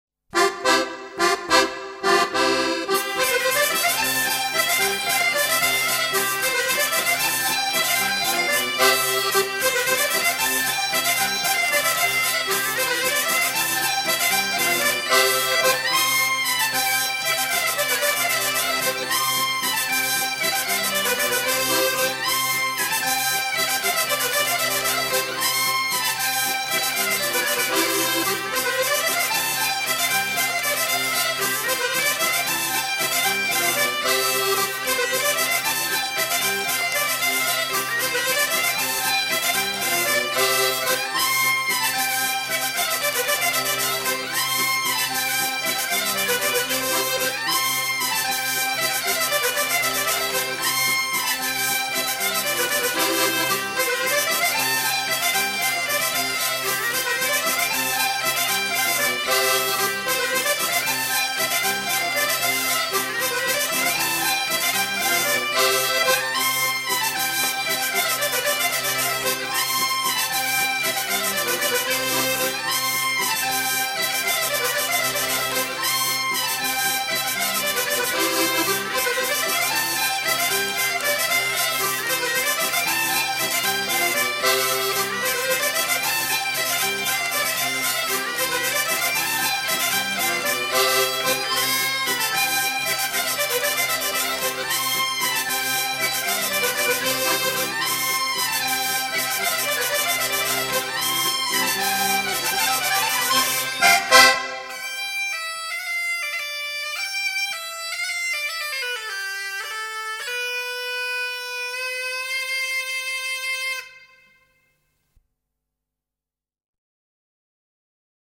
La Bourr�e
Mais ne nous trompons pas, l'oralit� de la transmission est source de richesse... et la bourr�e � trois temps est bien la reine des danses du pays morvandiau.